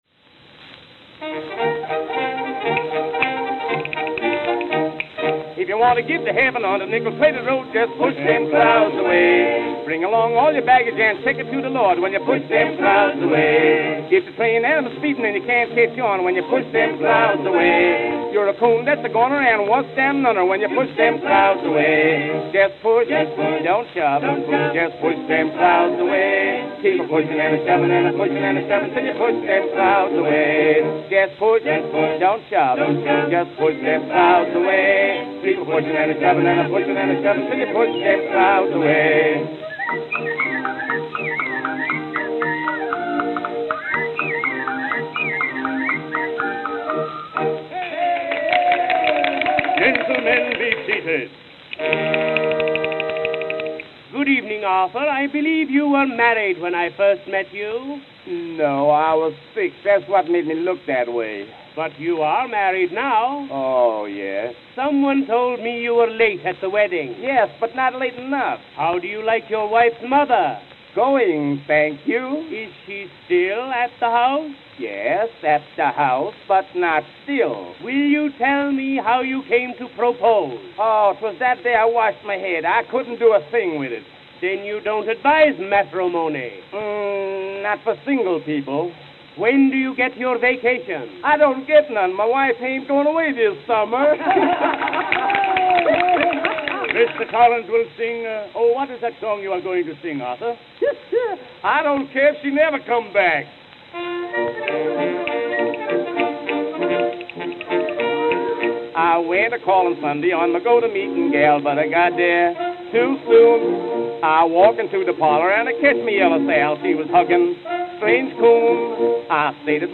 New York, New York New York, New York